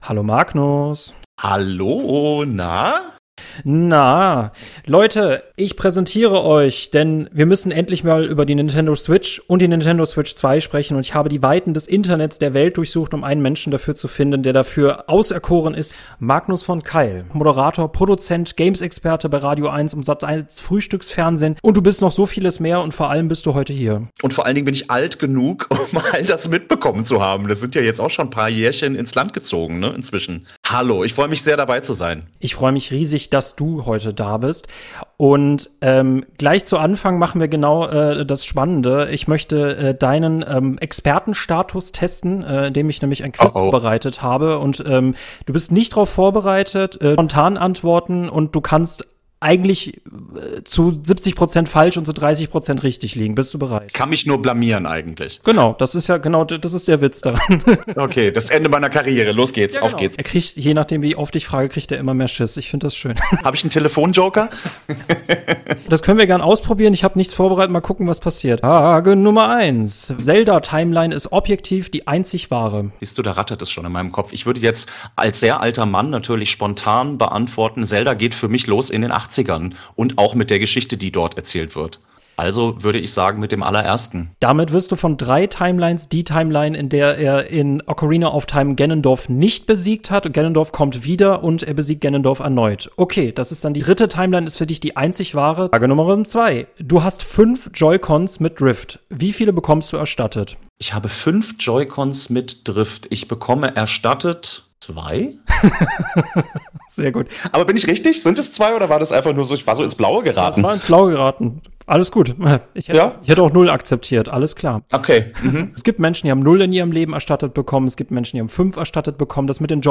Einfach eine chillige und entspannte Folge zum Entspannen.